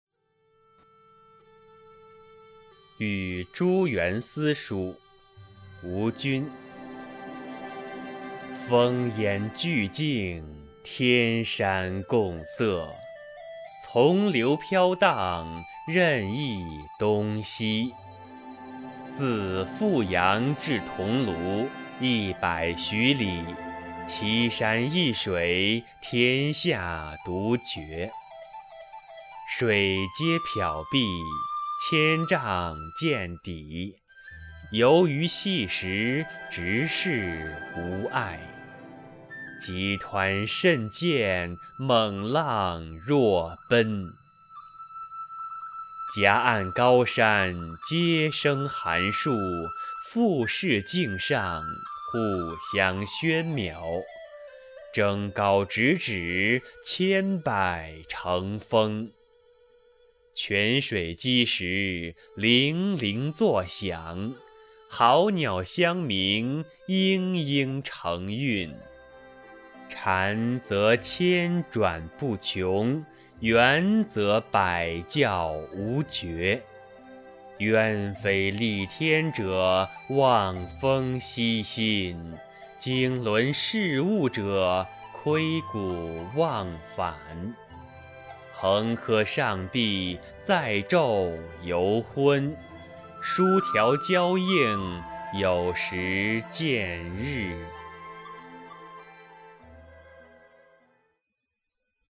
《与朱元思书》音频朗读